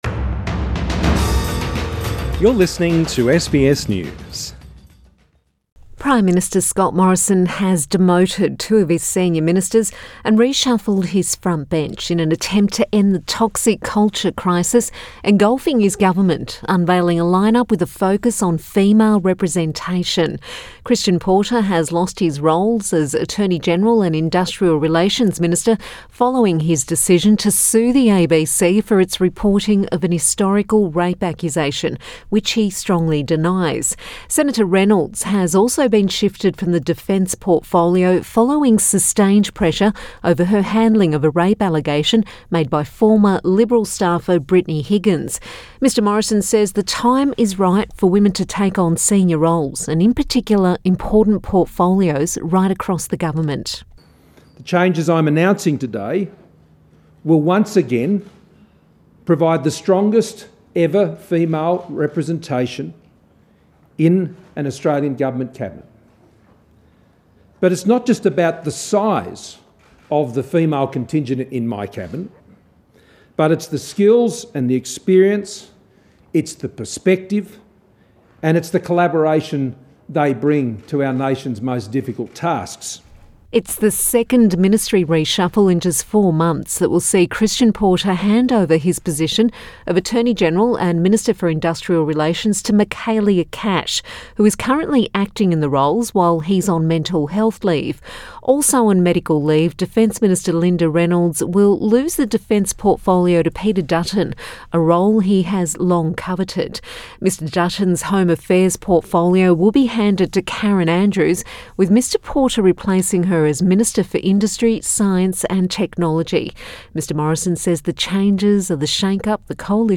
Prime Minister Scott Morrison addresses the media Source: AAP